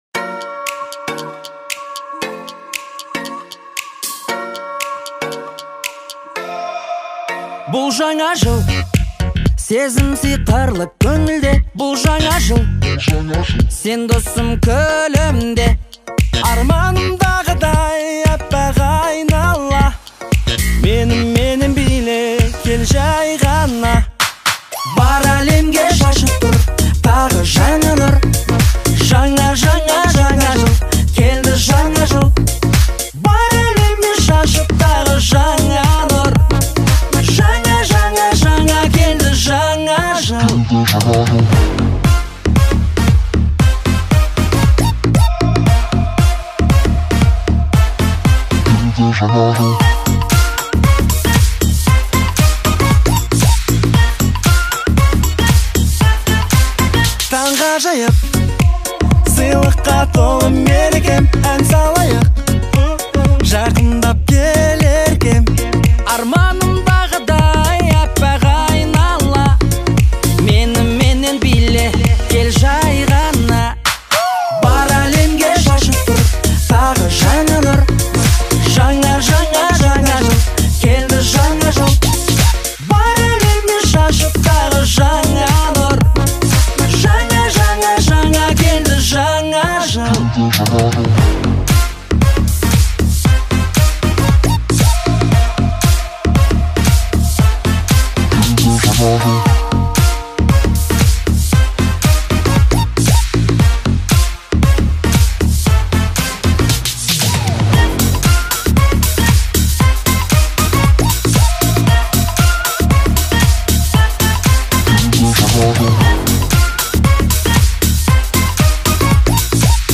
это яркий и жизнерадостный трек в жанре поп.